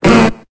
Cri de Ronflex dans Pokémon Épée et Bouclier.